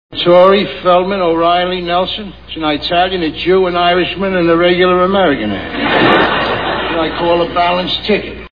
All in the Family TV Show Sound Bites